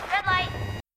• Качество: высокое
Звук с куклой говорящей Red light замри